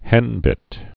(hĕnbĭt)